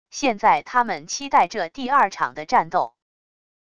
现在他们期待这第二场的战斗wav音频生成系统WAV Audio Player